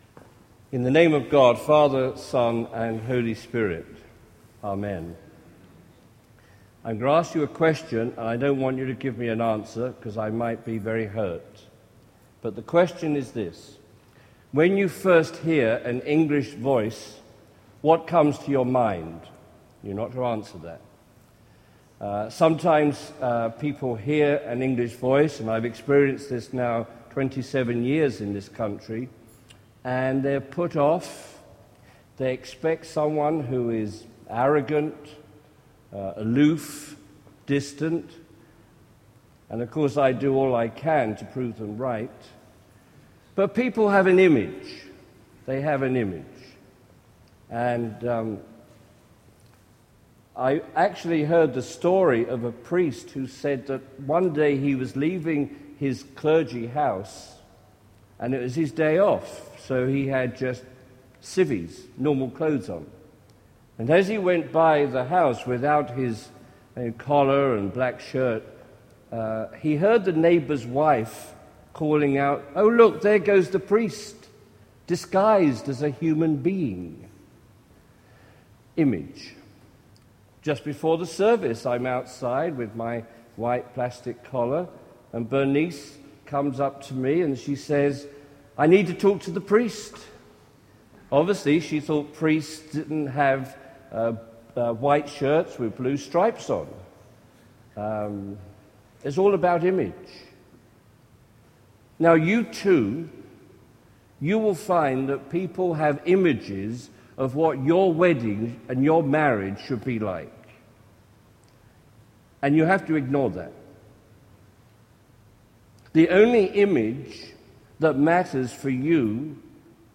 Click below to hear the sermon “Make a True Image”